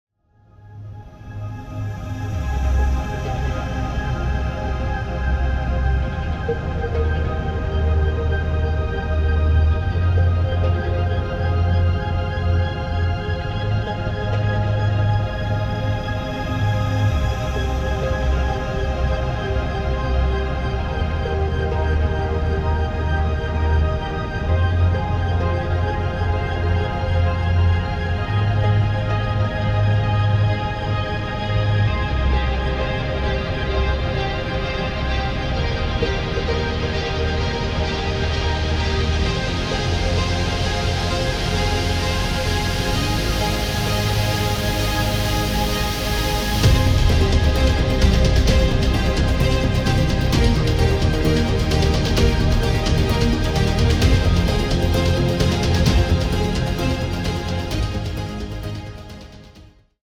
Meditation Version